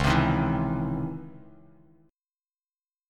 C#mM9 chord